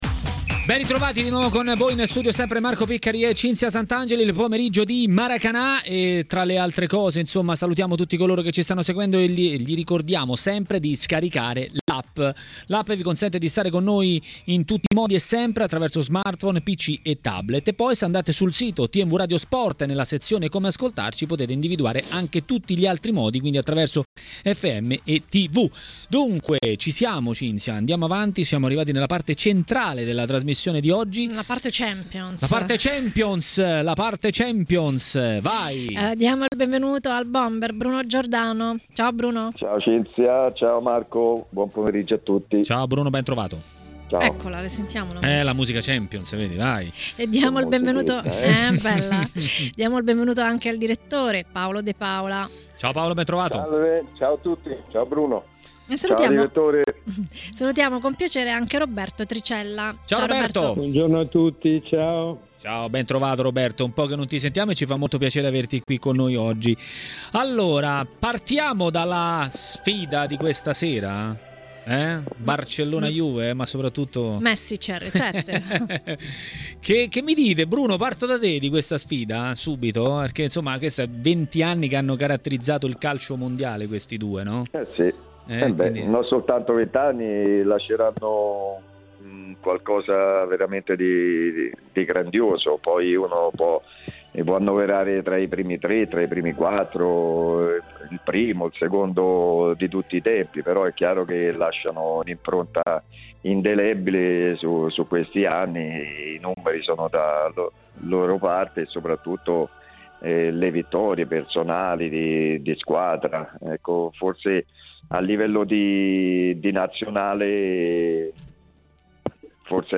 Per parlare di Champions League a TMW Radio, durante Maracanà, è intervenuto l'ex bomber e tecnico Bruno Giordano.